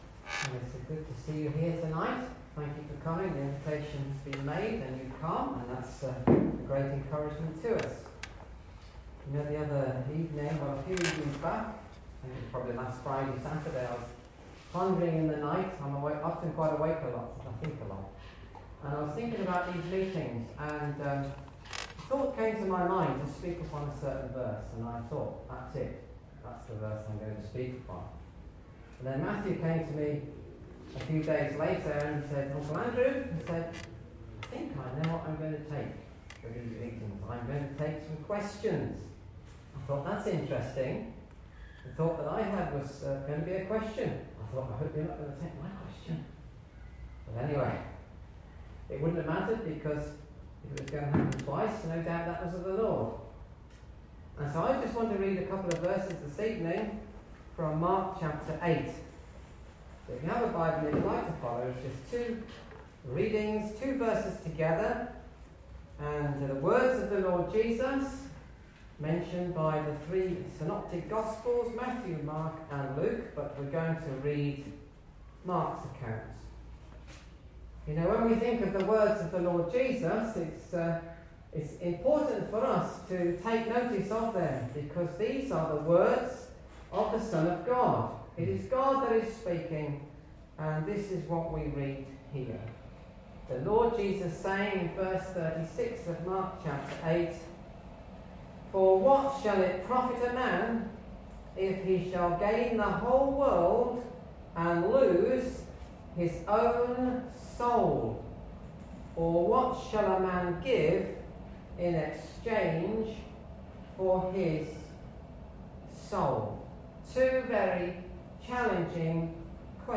Miscellaneous Gospel Messages
Acts 2:36-41 Service Type: Gospel